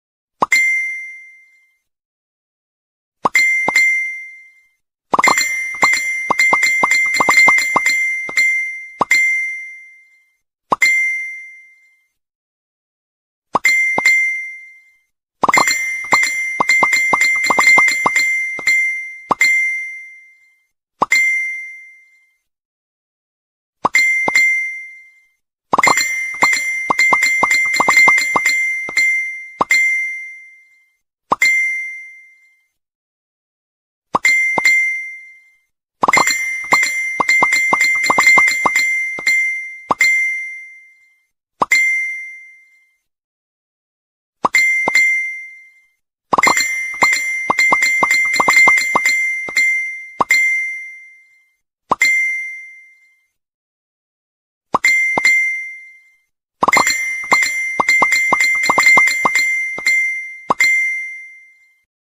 Genre: เสียงข้อความ